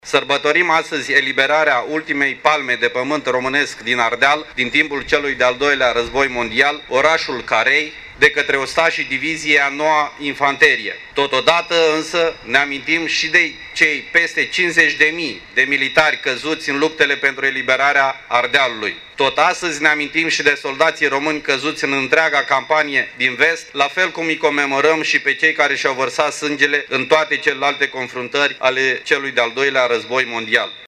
Prefectul de Iaşi, Marian Şerbescu a declarat, la rândul său, că ziua de 25 octombrie este o zi de sărbătoare, dar şi de comemorare, în acelaşi timp, a ostaşilor căzuţi pe toate câmpurile de luptă: